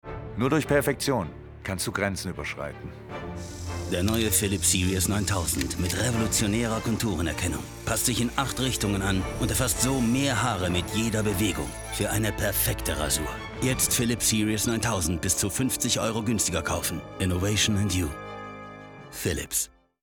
Profisprecher für Werbung, Synchron, Computerspiele, Hörspiele, Imagefilme
deutsch
Sprechprobe: Werbung (Muttersprache):